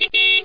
BEEP_12.mp3